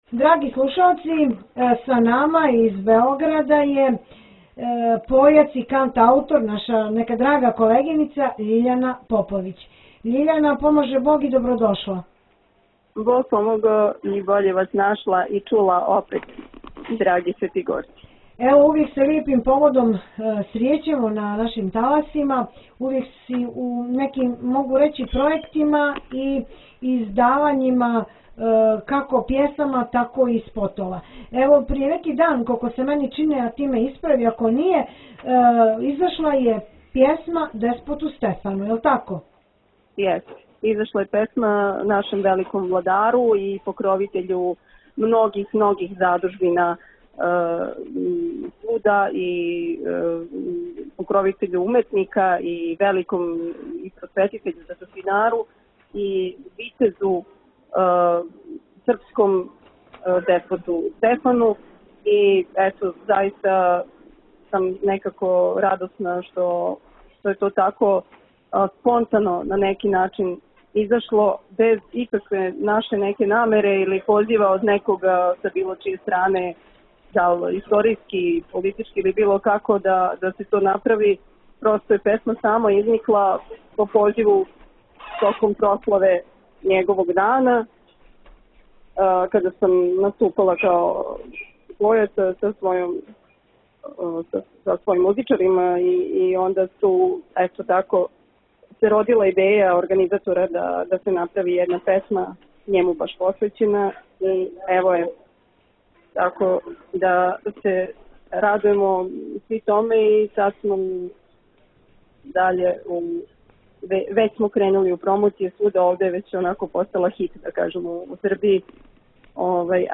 Појац и кантаутор